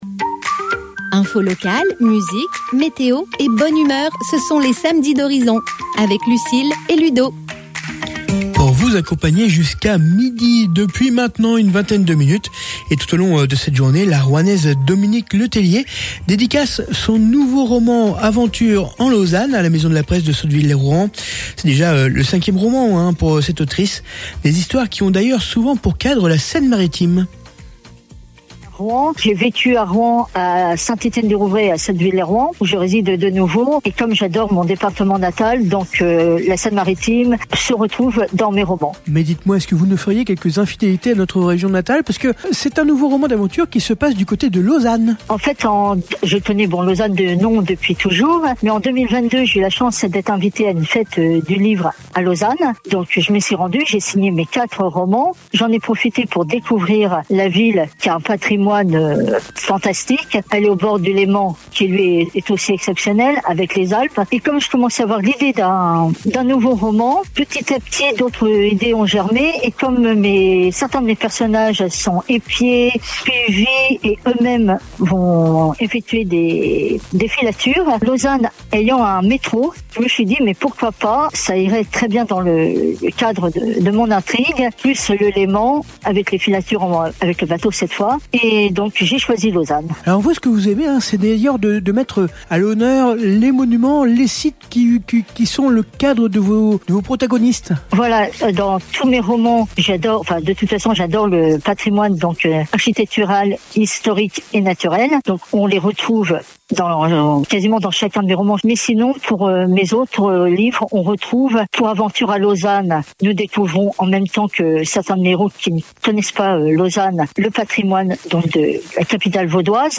Radio :